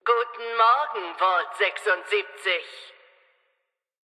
Beschreibung Lizenz Diese Datei wurde in dem Video-Spiel Fallout 76 aufgenommen oder stammt von Webseiten, die erstellt und im Besitz von Bethesda Softworks sind, deren Urheberrecht von Bethesda Softworks beansprucht wird.